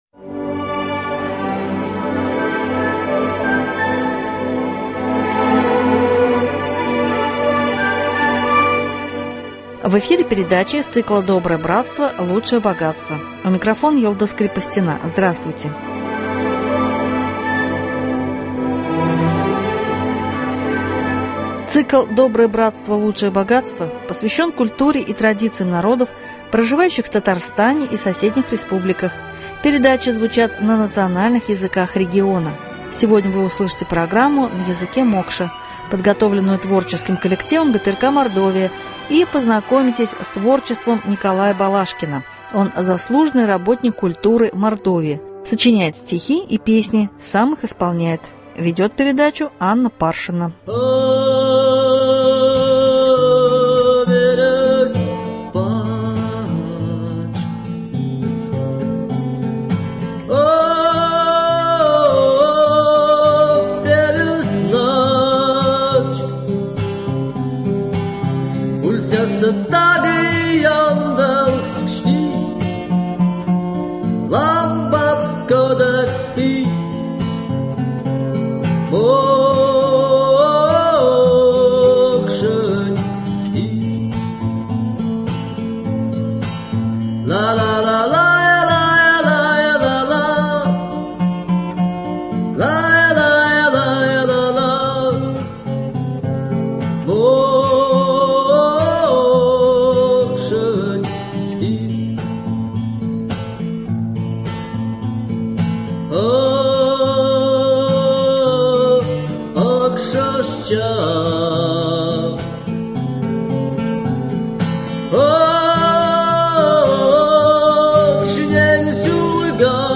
Программа из фондов радио Мордовии.